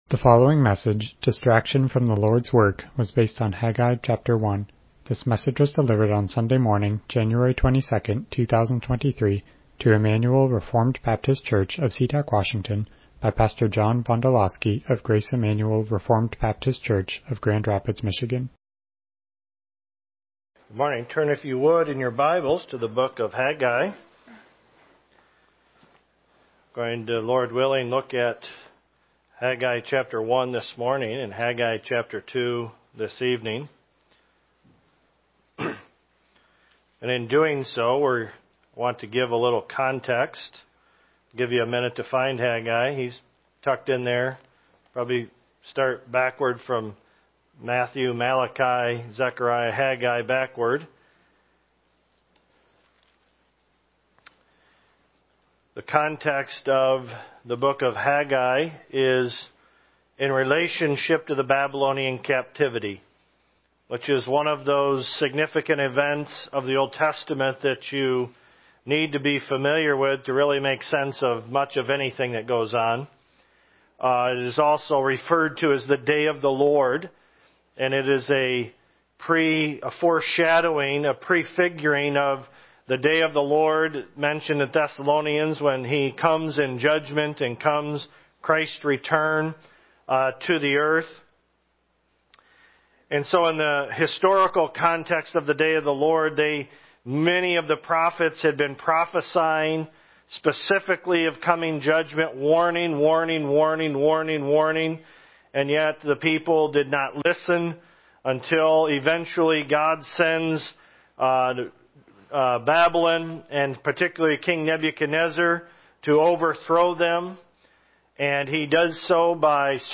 Haggai 1 Service Type: Morning Worship « Arthur Pink Biography